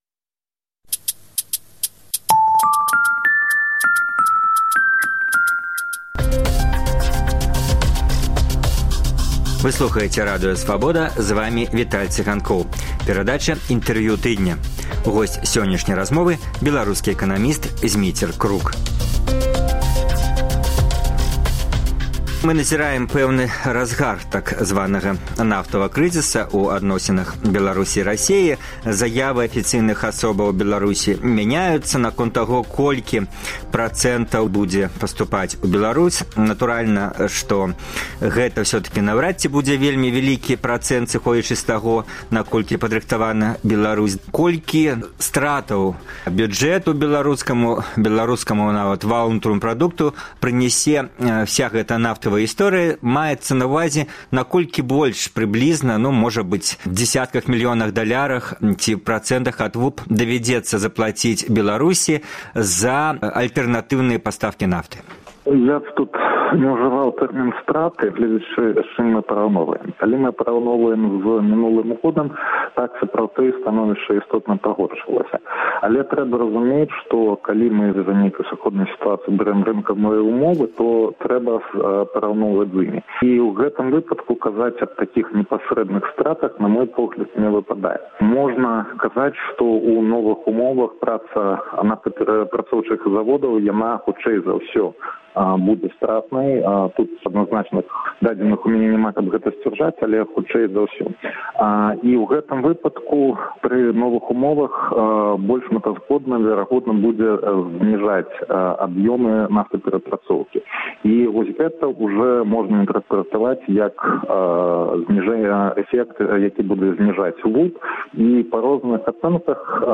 Інтэрвію тыдня